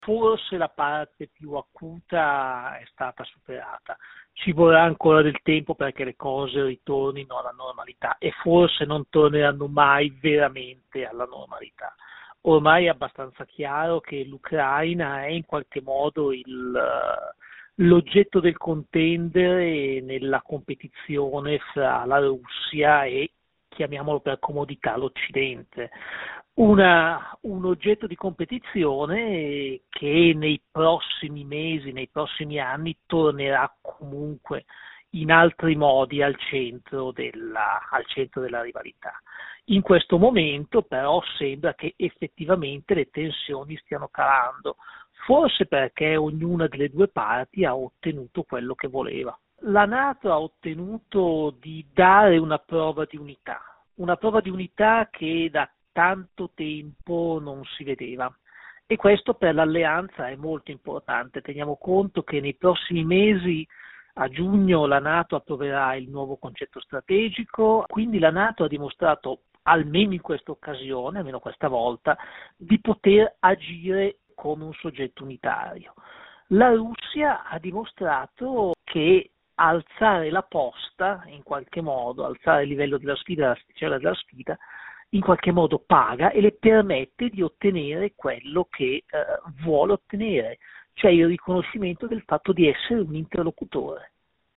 Il racconto della giornata di martedì 15 febbraio 2022 con le notizie principali del giornale radio delle 19.30. Una parte dei soldati russi dispiegati alle frontiere ha iniziato il rientro presso le proprie basi.